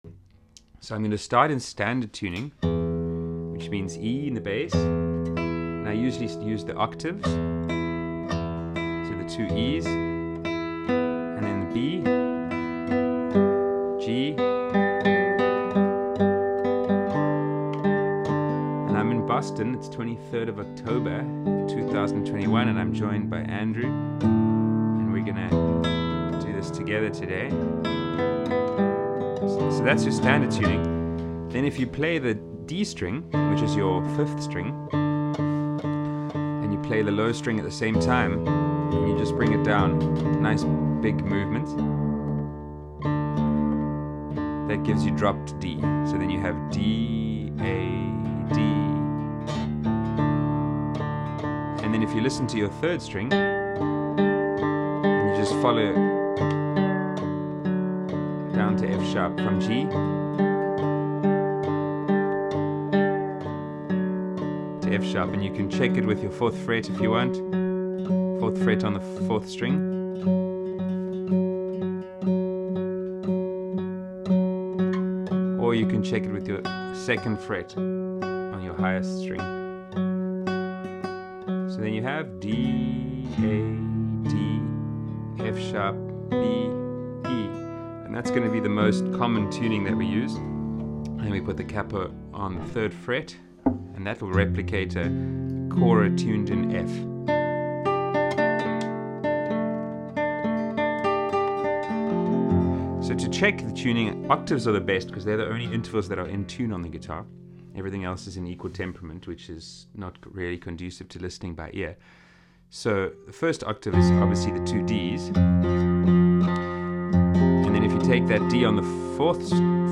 Video lessons uploaded after every online group class.